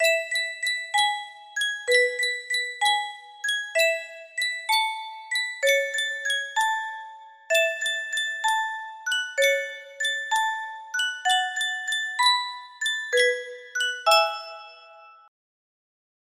Yunsheng Caja de Musica - La Cancion de San Fermin 4571 music box melody
Full range 60